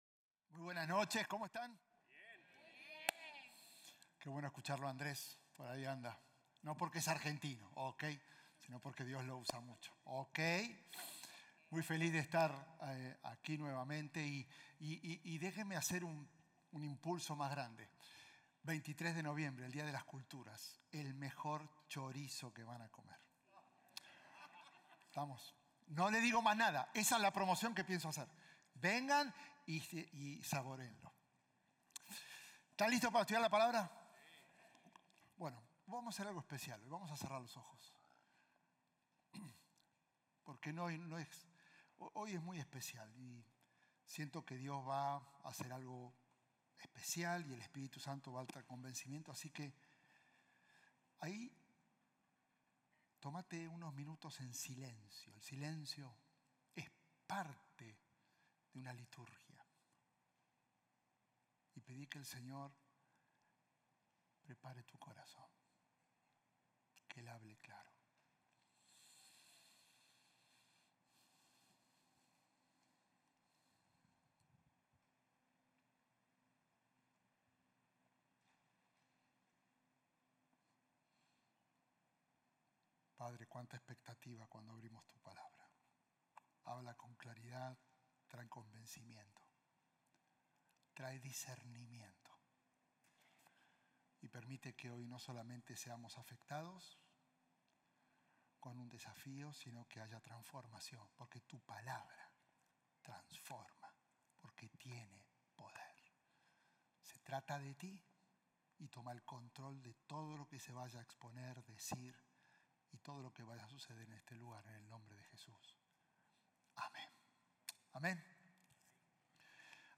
Un mensaje de la serie "“Si…” - NK."